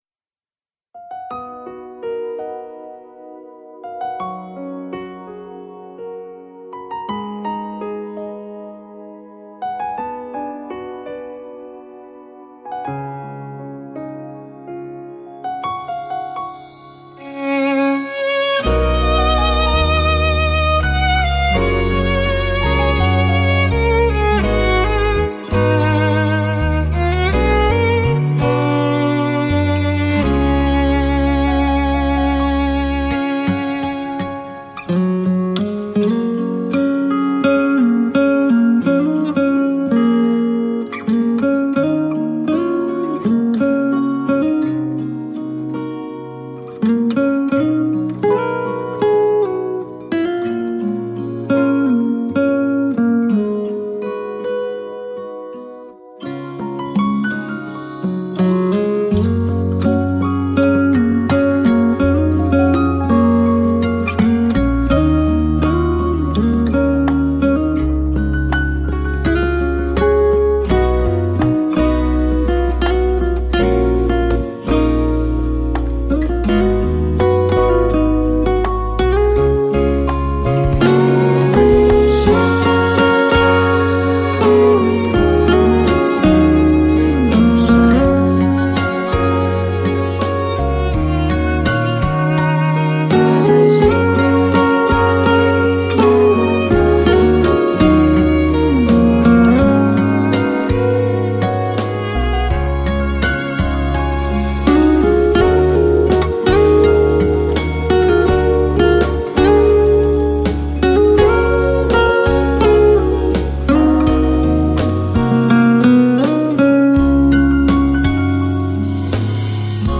演奏精准到位 细腻感人
HiFi吉他典范
高频泛音通透清晰
清脆 明快 自然 此起彼落 演奏得心应手 细腻动人 铿锵传神 引人入胜